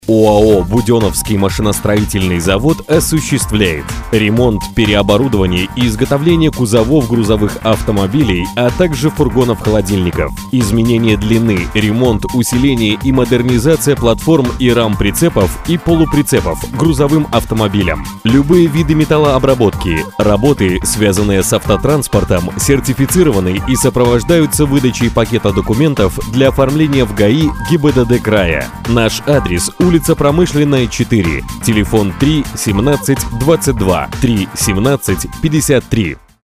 Новый рекламный аудио-ролик